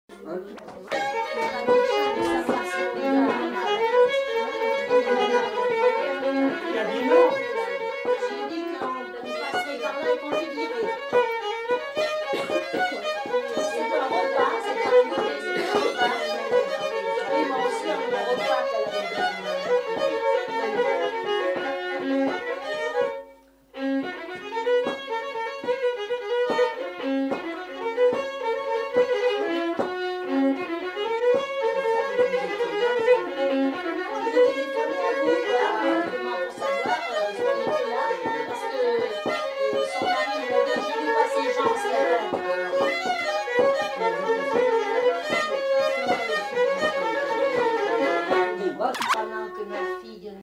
Aire culturelle : Limousin
Genre : morceau instrumental
Instrument de musique : violon
Danse : bourrée
Notes consultables : Le second violon est joué par un des enquêteurs.